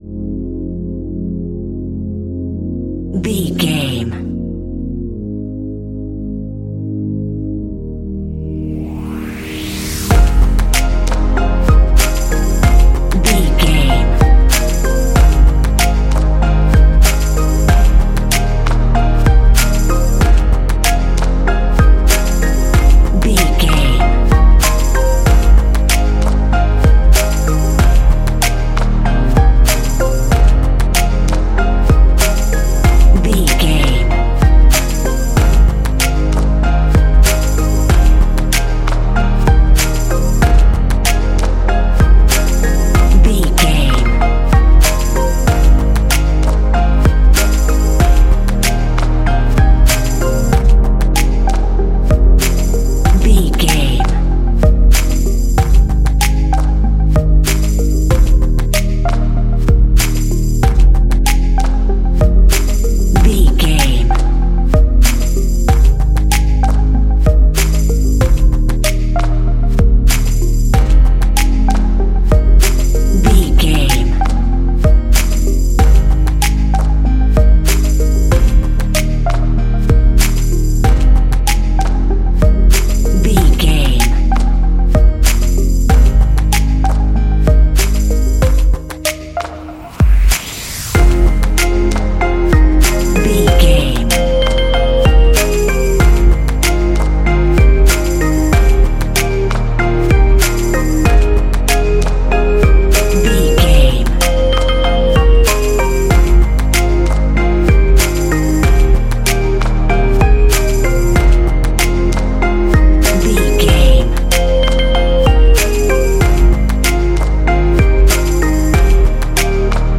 Ionian/Major
F♯
house
electro dance
synths
techno
trance
instrumentals